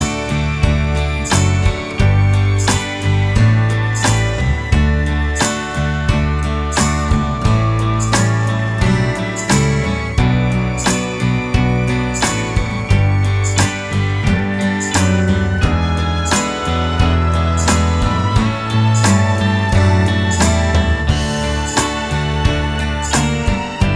Irish Traditional